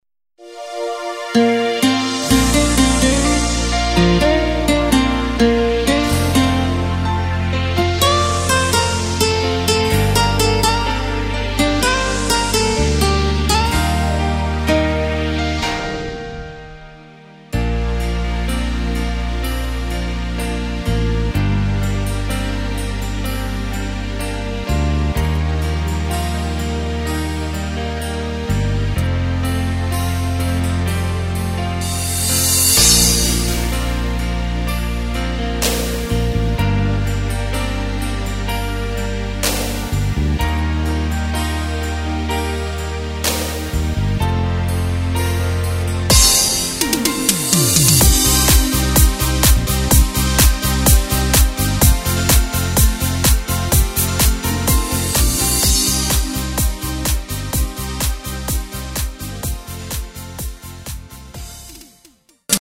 Takt: 4/4 Tempo: 126.00 Tonart: Bb
Disco-Fox
mp3 Playback Demo